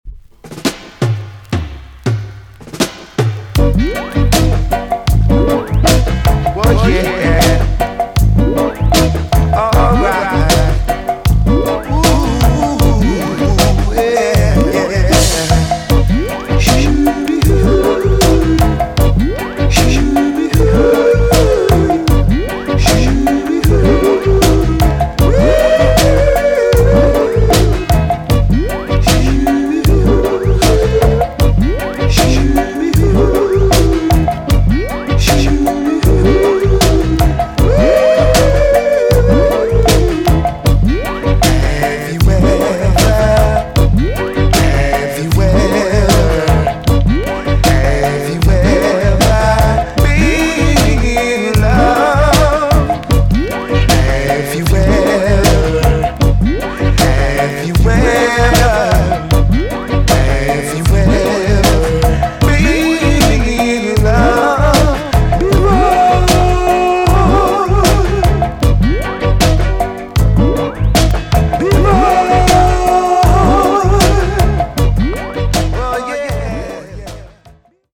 TOP >DISCO45 >80'S 90'S DANCEHALL
EX- 音はキレイです。